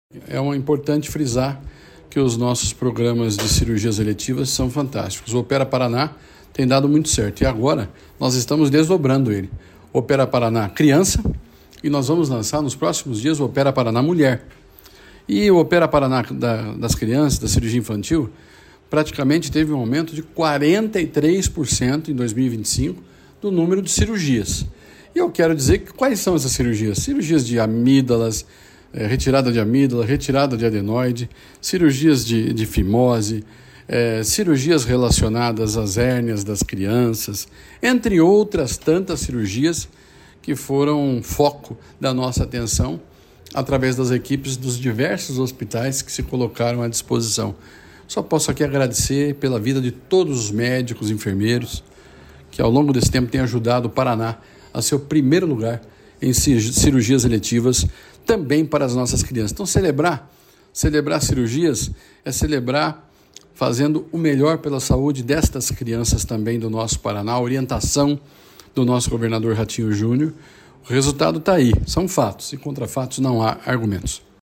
Sonora do secretário da Saúde, Beto Preto, sobre os procedimentos em crianças pelo Opera Paraná